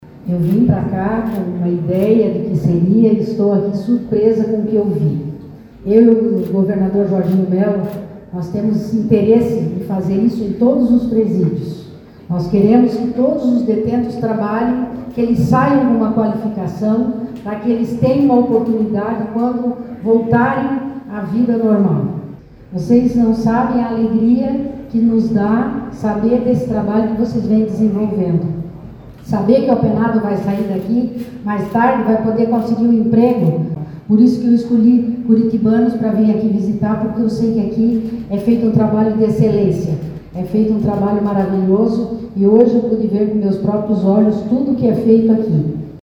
Durante a vista, a vice-governadora se disse surpresa com o trabalho de excelência feito na penitenciária:
SECOM-Sonora-vice-governadora-1.mp3